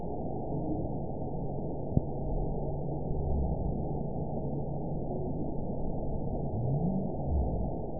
event 922129 date 12/26/24 time 22:50:30 GMT (11 months, 1 week ago) score 8.92 location TSS-AB04 detected by nrw target species NRW annotations +NRW Spectrogram: Frequency (kHz) vs. Time (s) audio not available .wav